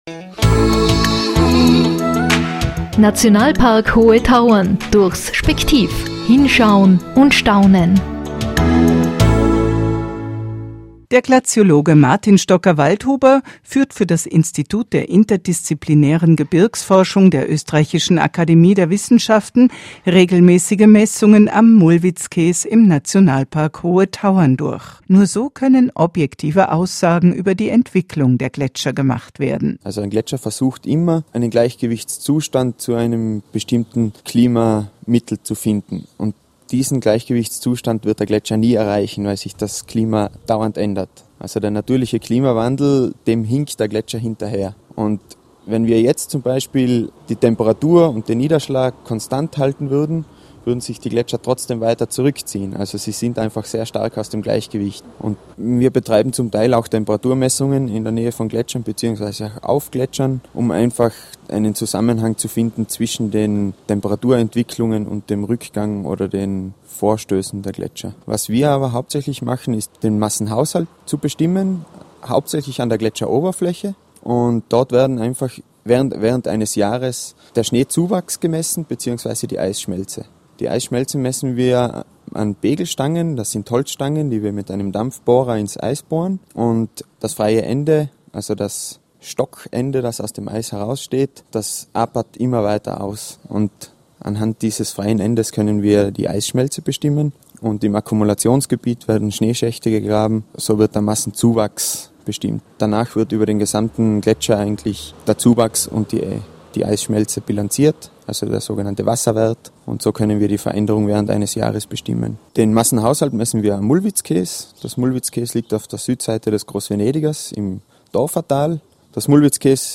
Um über der ständigen Entwicklung der Gletscher auf den Laufenden zu bleiben werden von Glaziologen regelmäßig Messungen an diesen durchgeführt. Im Interview